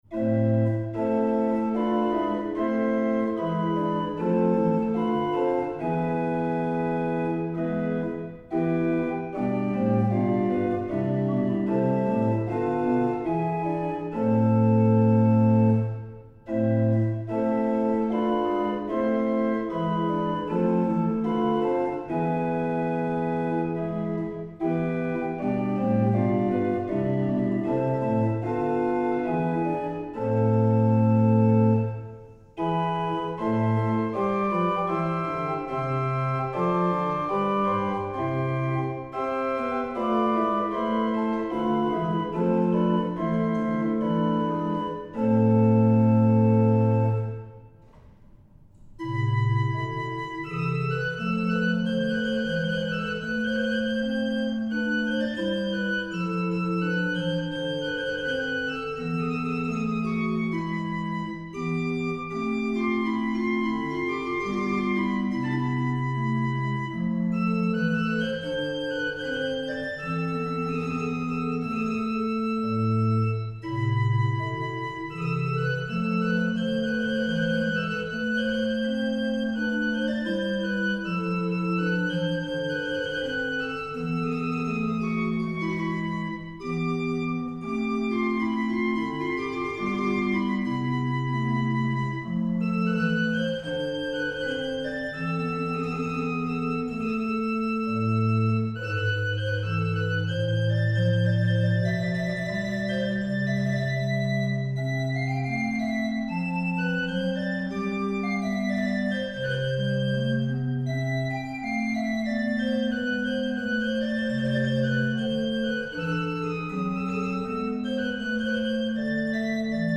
Organy
J.S. Bach Wer nur den lieben Gott läßt walten [chorał i opr.